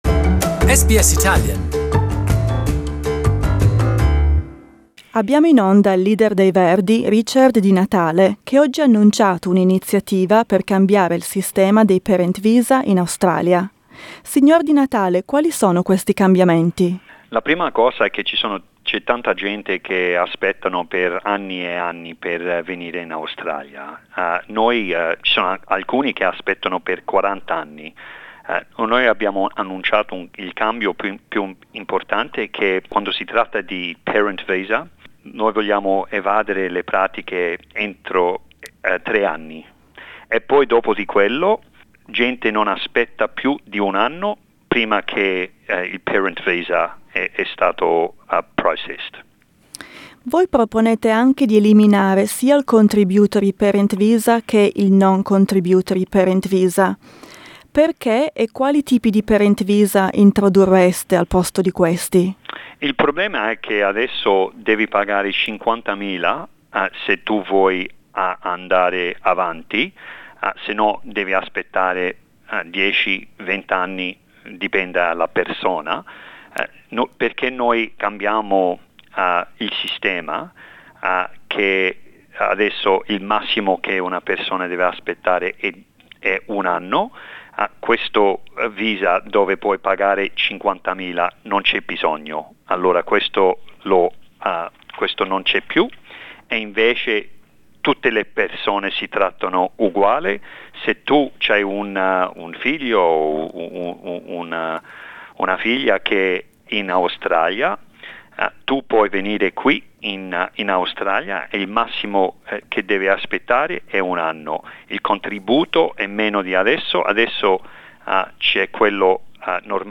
Greens Senator Richard Di Natale tells SBS how his party plans to make it quicker and easier for migrants to bring their parents to live with them in Australia.